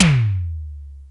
Tom-01.wav